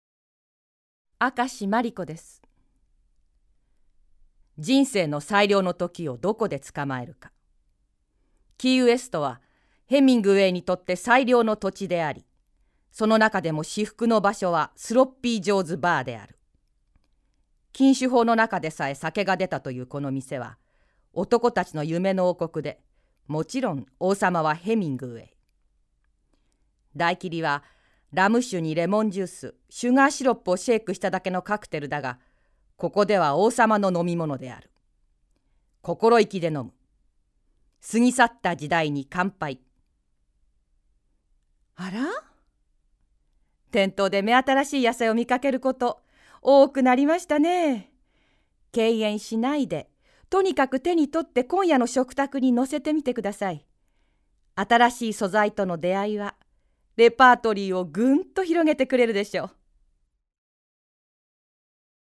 ボイスサンプル